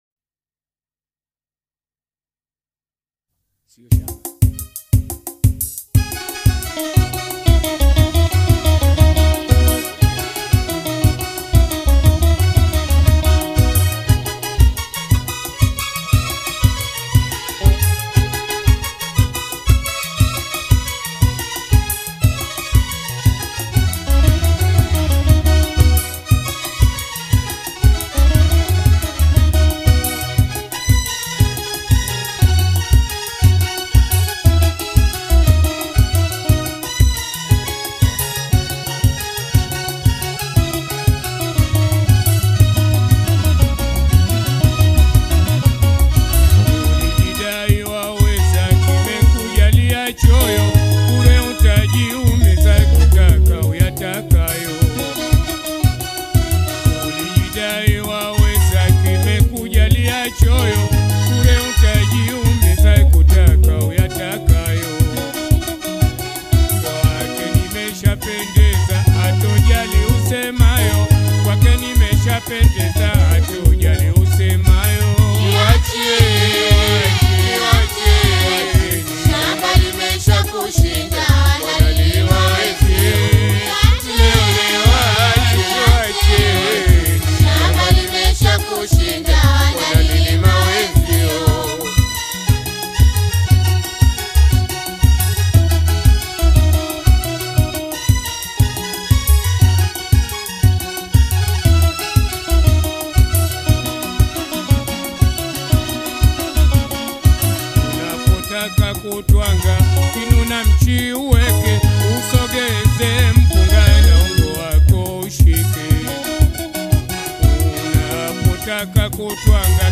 Taarab
enchanting voice